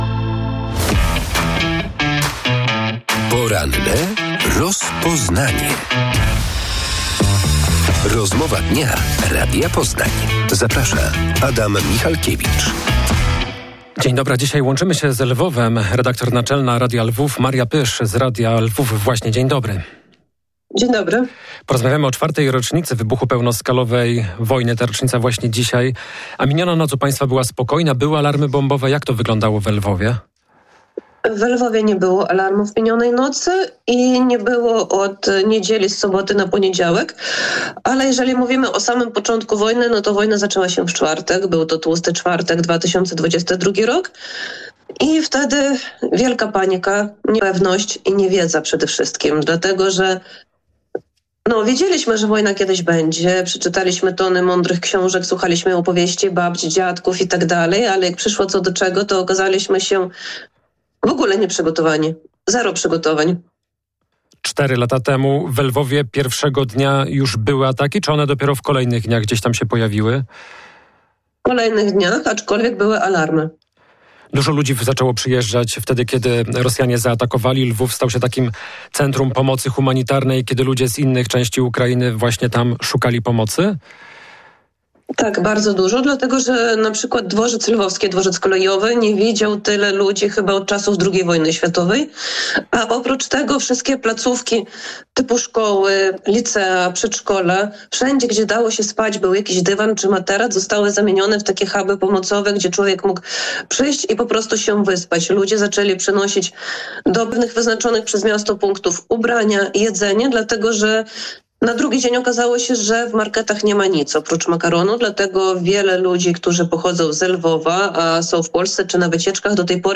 Cztery lata temu Rosjanie zaatakowali Ukrainę. 24 lutego 2022 roku rozpoczęła się pełnoskalowa wojna. W porannej rozmowie Radia Poznań